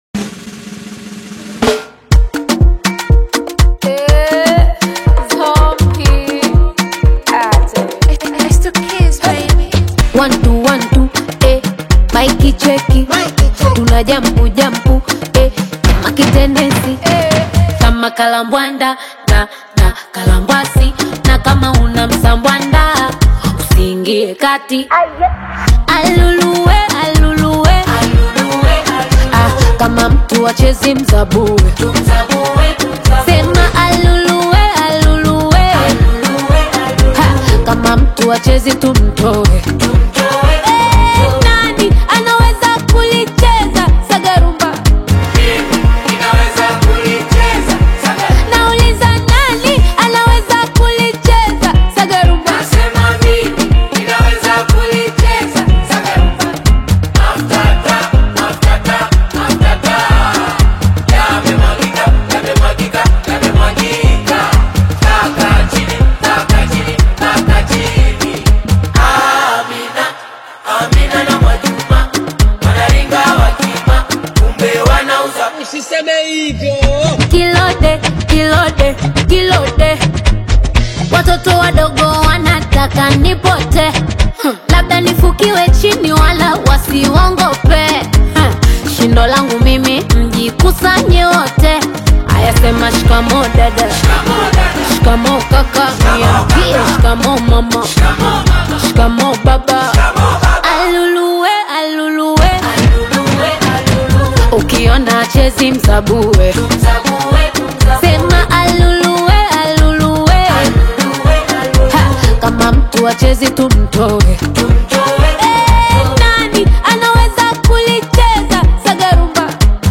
Sensational songstress, songwriter